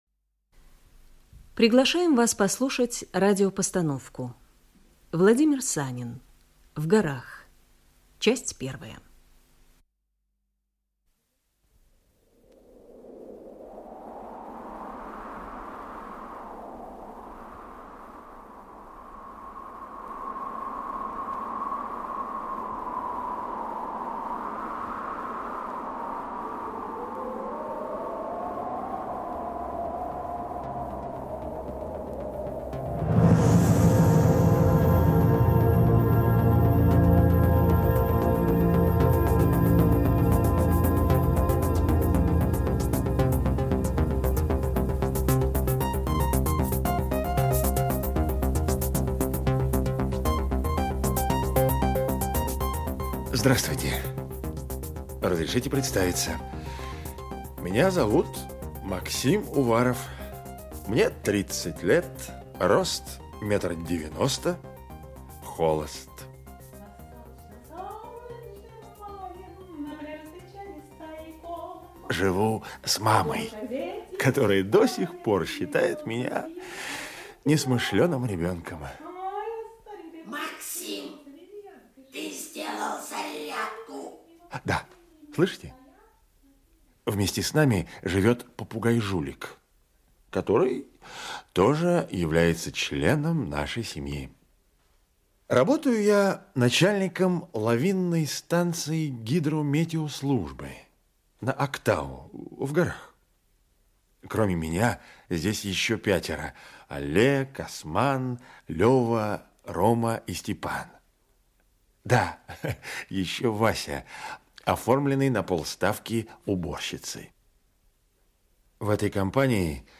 Аудиокнига В горах. Часть 1 | Библиотека аудиокниг
Часть 1 Автор Владимир Санин Читает аудиокнигу Евгений Киндинов.